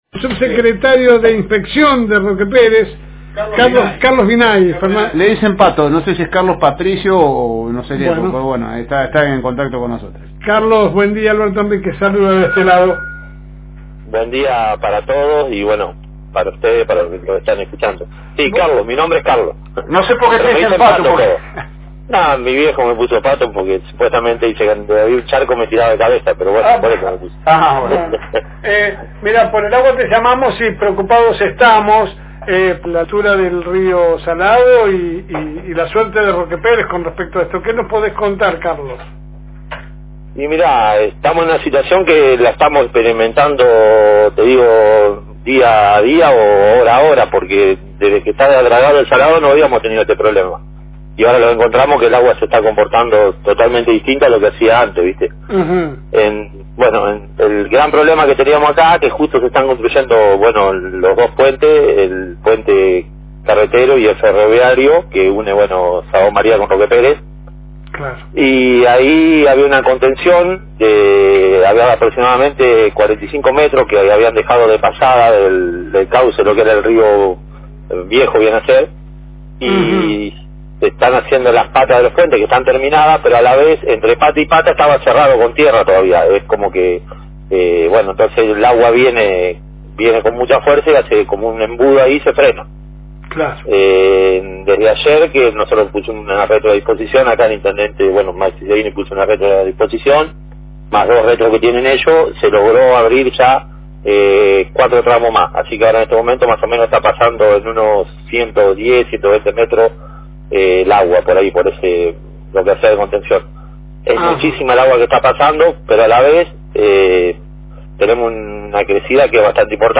Para conocer un poco más sobre el tema en LSM charlamos con Carlos Vinay Sub Secretario de Inspección del Municipio de Roque Pérez que nos narraba para la Fm reencuentro las consecuencias y los trabajos que se llevan adelante.